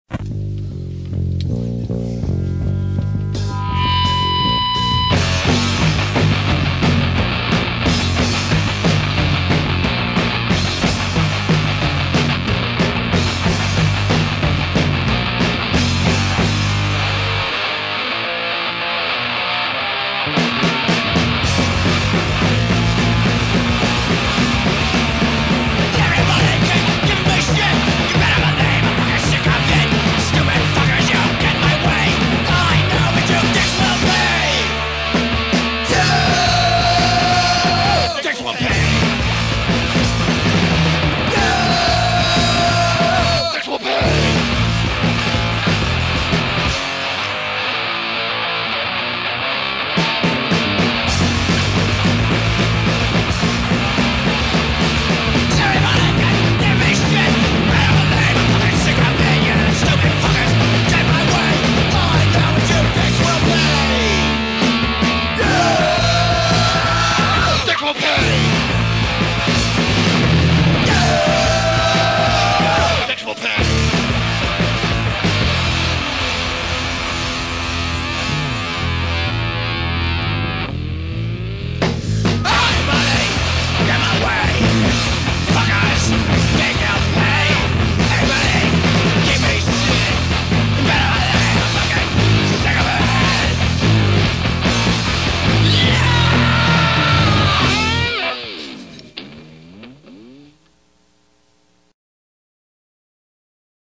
hardcore punk rock
punk rock See all items with this value